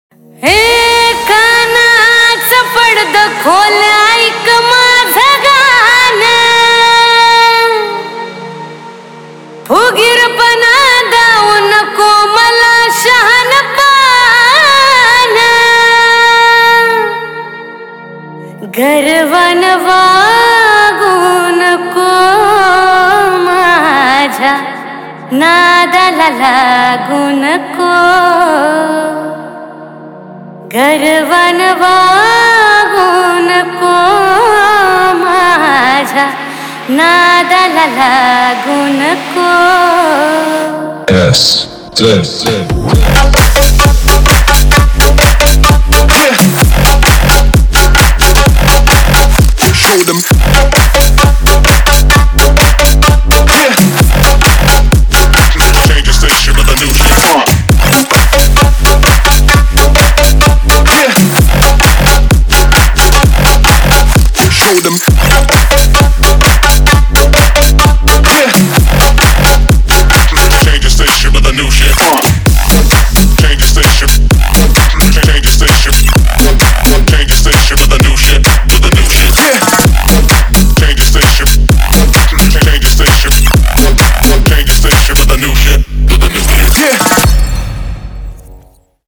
Category: MARATHI DJ